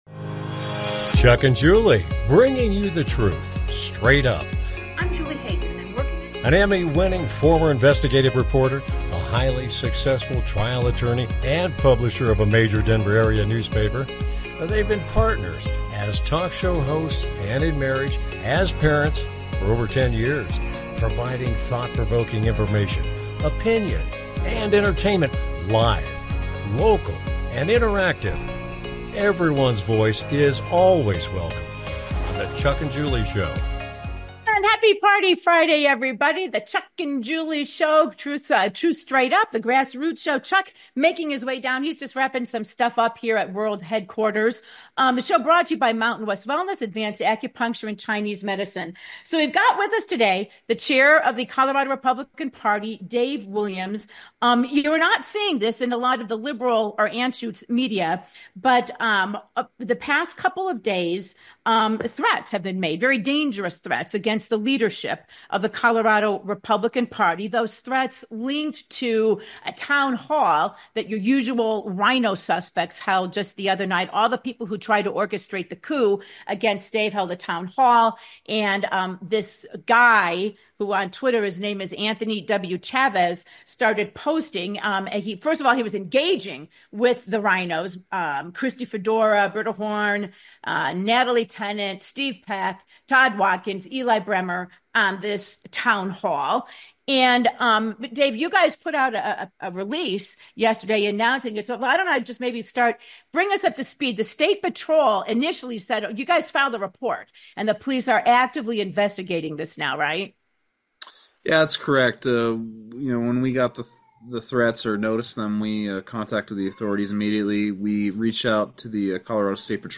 RINOs linked to threats of violence against Colorado Republican Party elected officials. Chair Dave Williams joins the show.